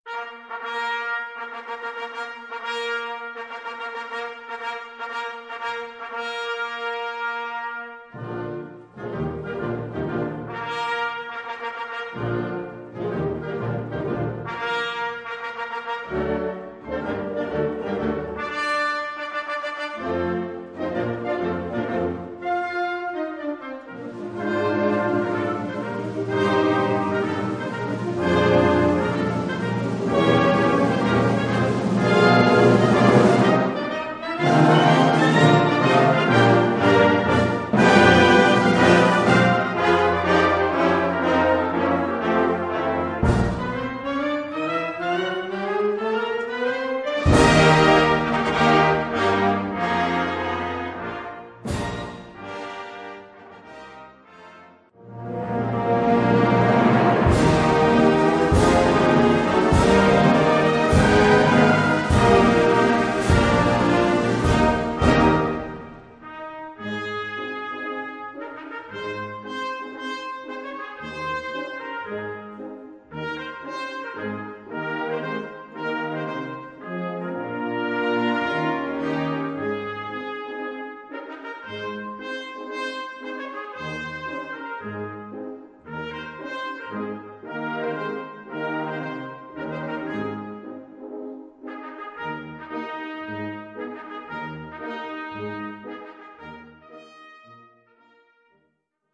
Gattung: Konzertmarsch
Besetzung: Blasorchester